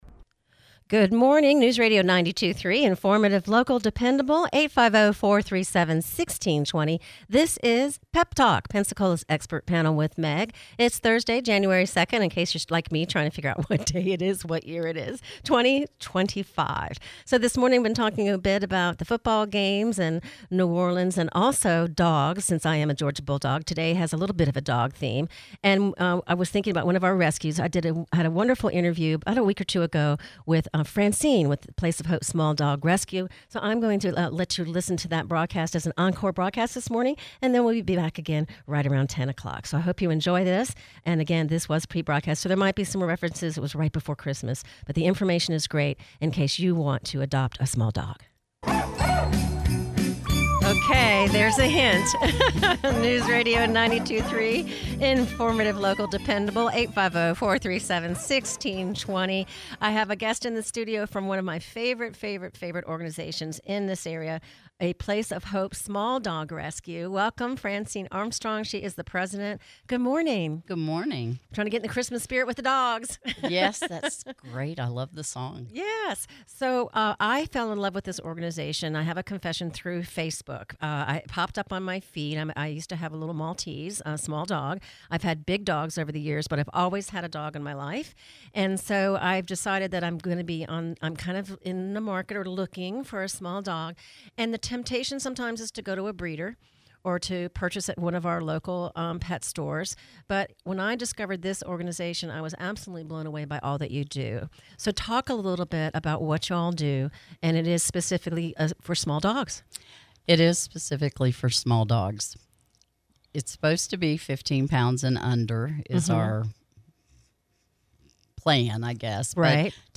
Encore brodacast on PEP Talk